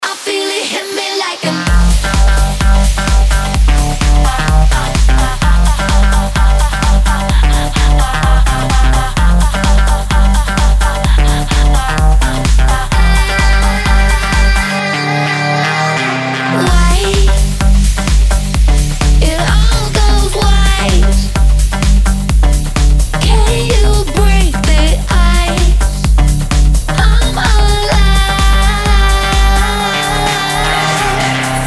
женский голос
dance
club
house